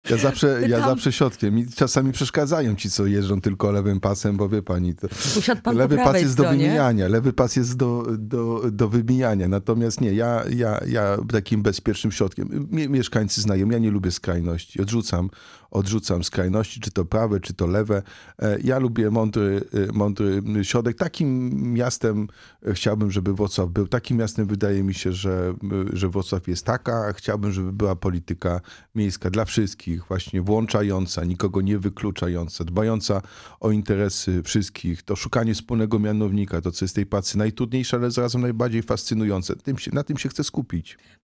Projekt budżetu na 2025 r., audyt w piłkarskim Śląsku Wrocław, sprawa zarzutów ws. Collegium Humanum – to główne tematy w naszej rozmowie z prezydentem Wrocławia.